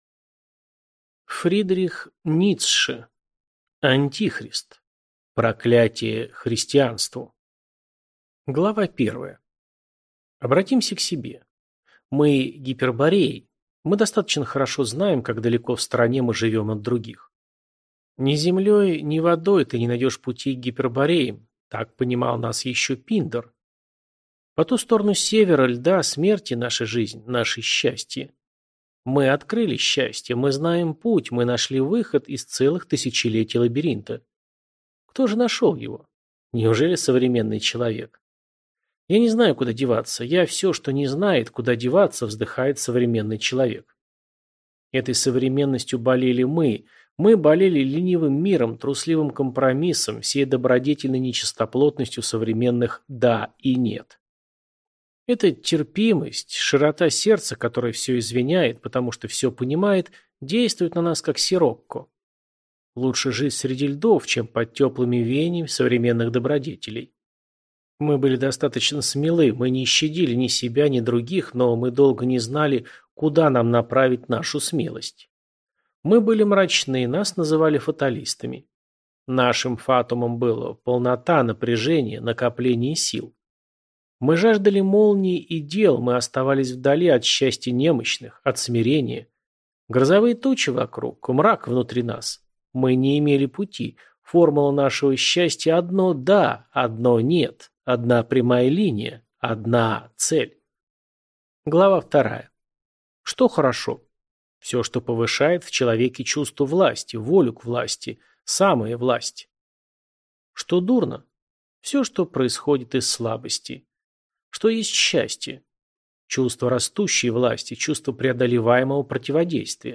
Аудиокнига Антихрист. Ecce Homo. Сумерки идолов | Библиотека аудиокниг